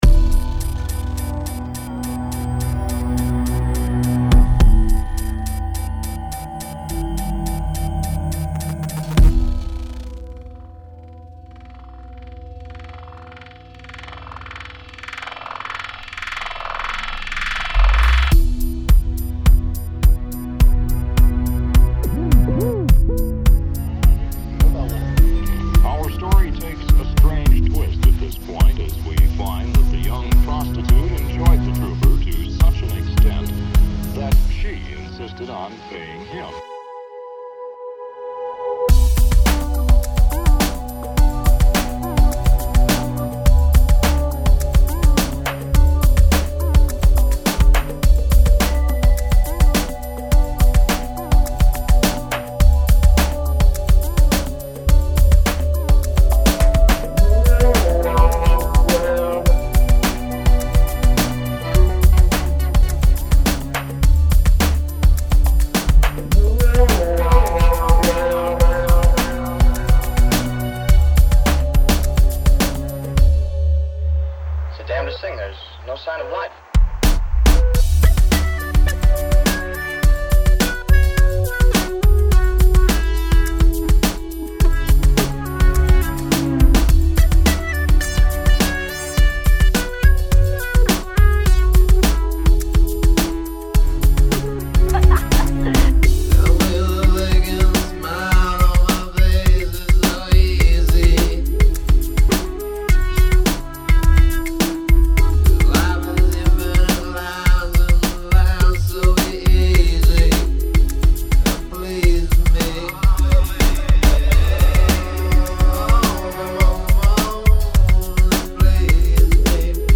dance/electronic
Leftfield/noise
Ambient
Breaks & beats